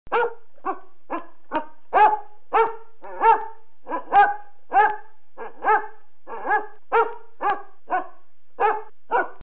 Bassett Hound Barking
Fun Sounds